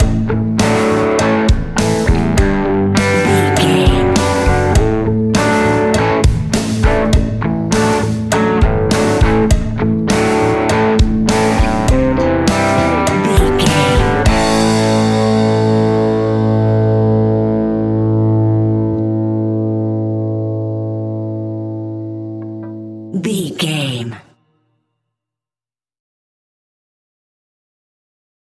Epic / Action
Fast paced
Mixolydian
hard rock
heavy metal
blues rock
distortion
rock guitars
Rock Bass
heavy drums
distorted guitars
hammond organ